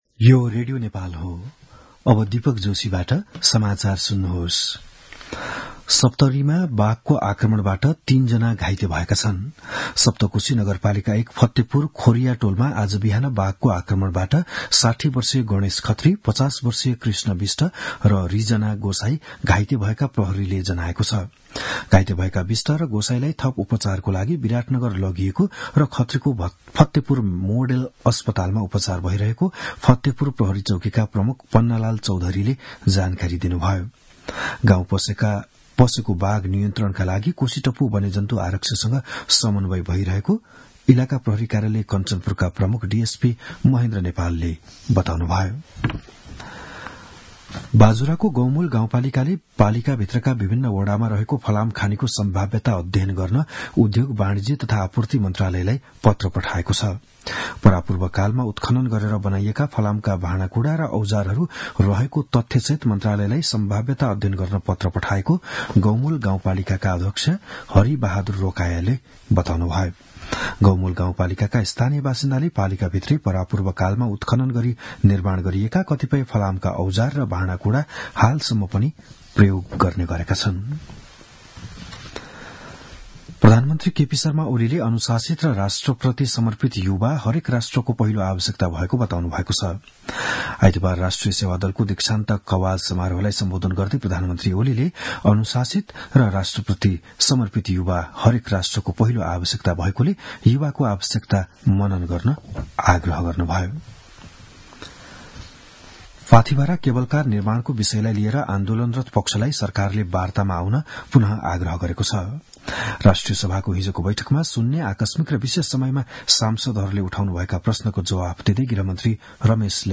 बिहान ११ बजेको नेपाली समाचार : २० फागुन , २०८१
11-am-news.mp3